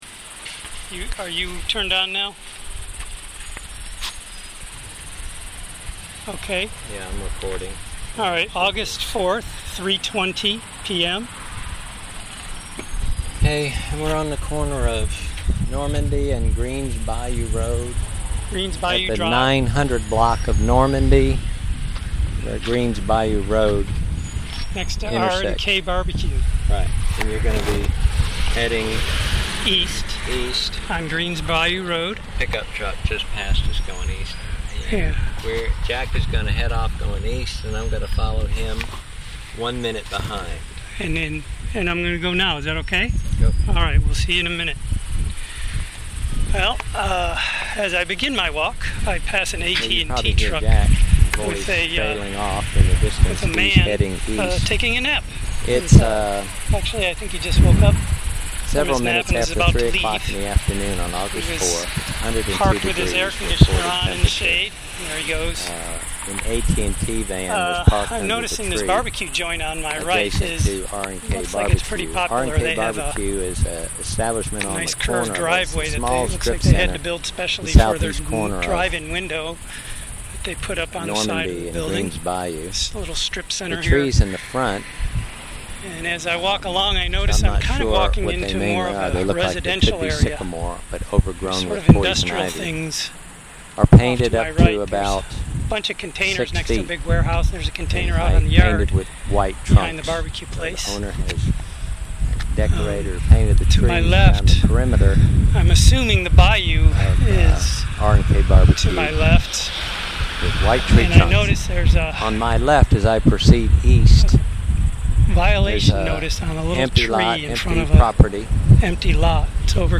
Because there are two of us, we used two recorders to capture our observations and thoughts.
Using two recorders at the same time but in different locations creates a kind of spatial “phase shifting” wherein the sound begins in sync, then goes out of sync, and finally resolves itself as it comes back into sync.